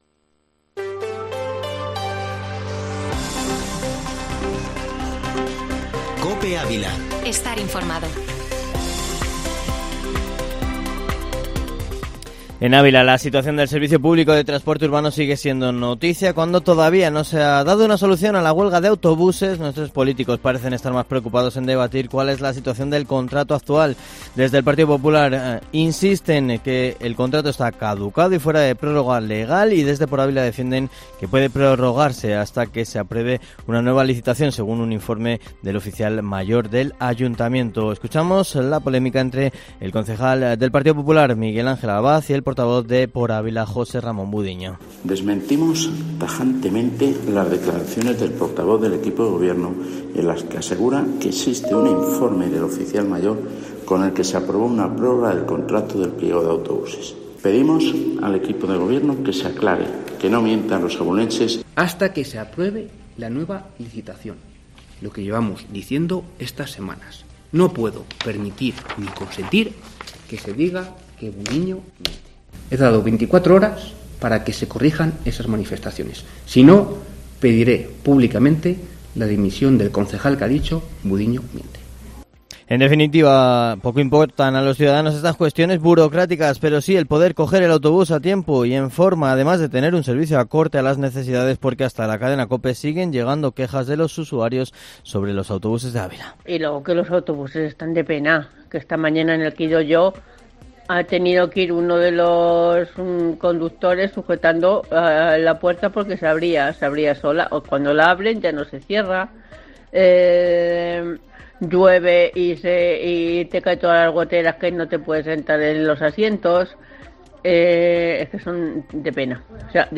Informativo Matinal Herrera en COPE Ávila -13-mayo